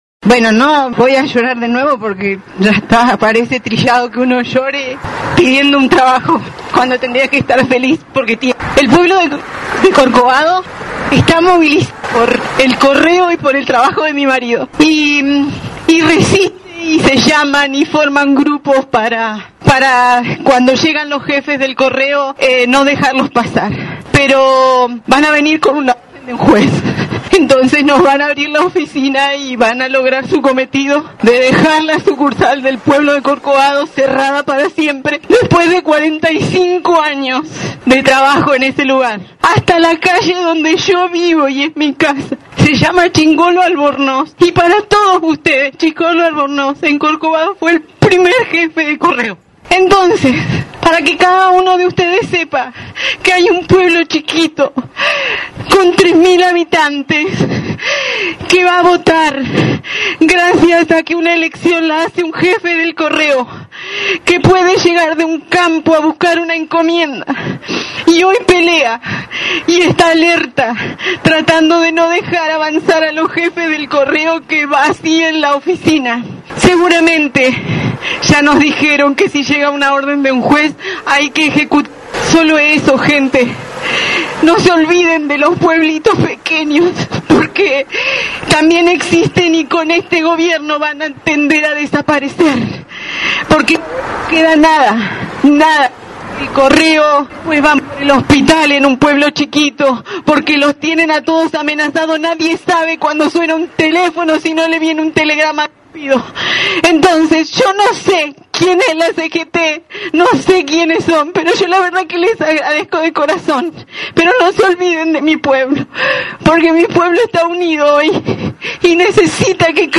Durante el acto realizado en la plazoleta de los trabajadores en Ameghino y Pellegrini, hicieron uso de la palabra dando a conocer la situación que están atravesando.